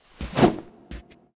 HighGroundRoyaleNetcode / Assets / Sounds / Character / Imp / mn_imp_attk_a.wav